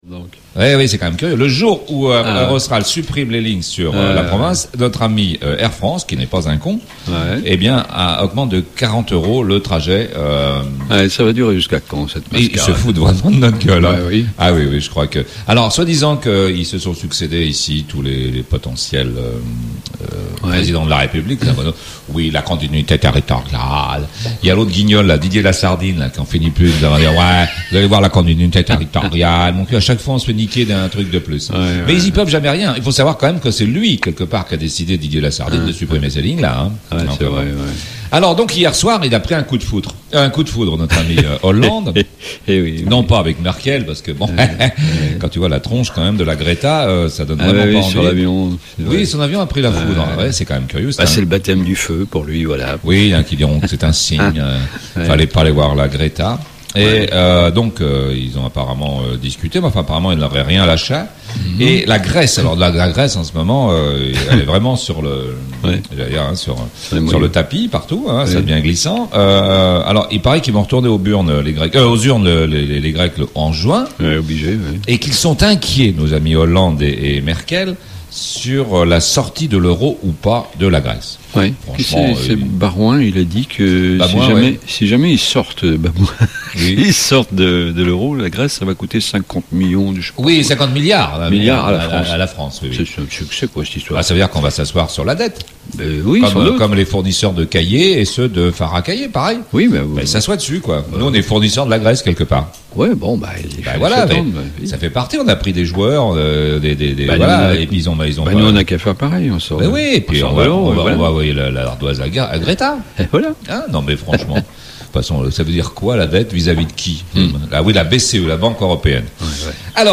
THALUSSA du mercredi 16 mai 2012 REVUE DE PRESSE Mercredi 16 Mai 2012 THALUSSA 16 mai 2012.mp3 (5.62 Mo) INFO REUNION Ajouter un commentaire… en rapport avec le contenu de l'article…